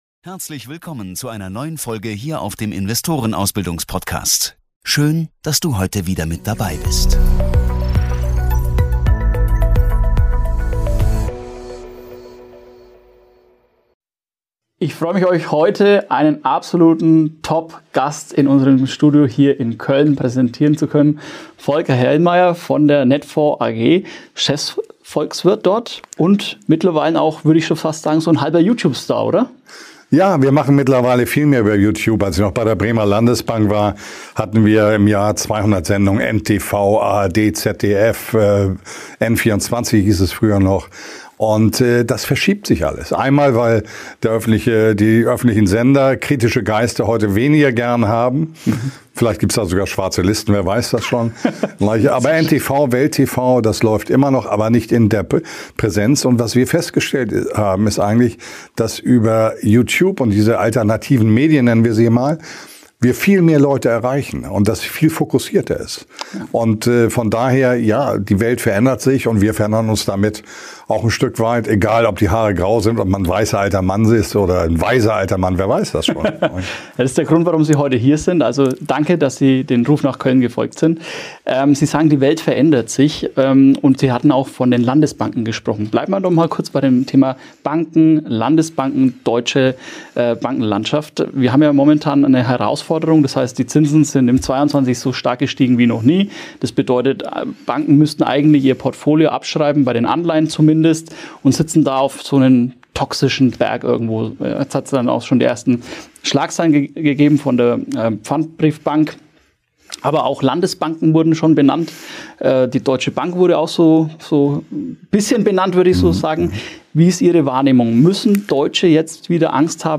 In diesem Interview präsentiert Folker Hellmeyer, Chefvolkswirt bei Netfonds, seine Analysen zu aktuellen wirtschaftlichen und geopolitischen Herausforderungen.